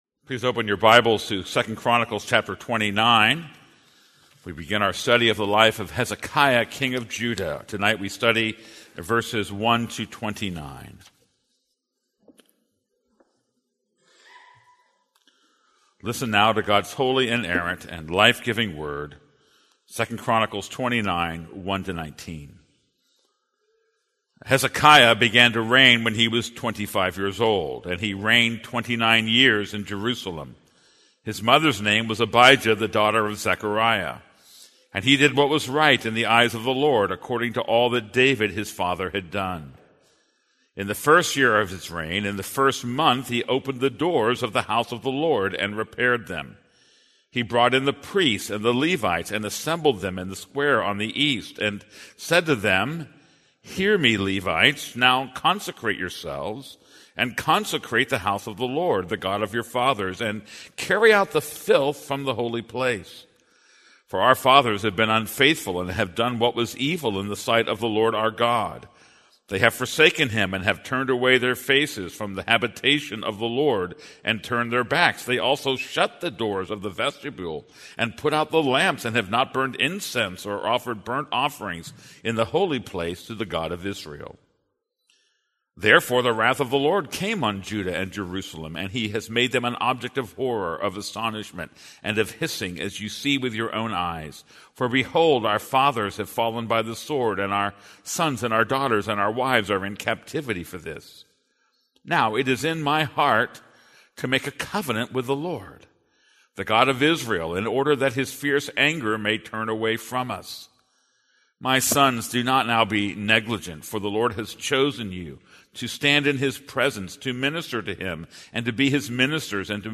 This is a sermon on 2 Chronicles 29:1-19.